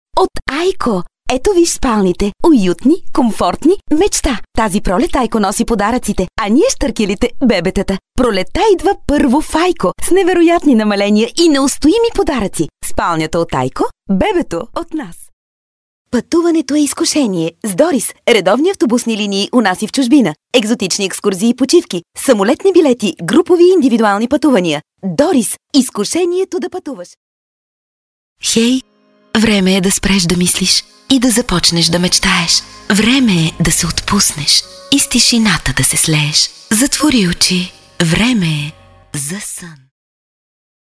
Weiblich